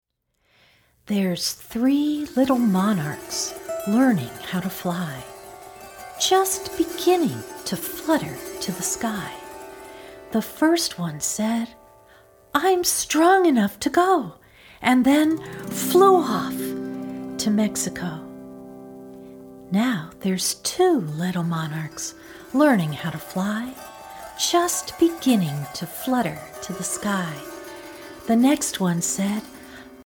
A wonderfully engaging fingerplay!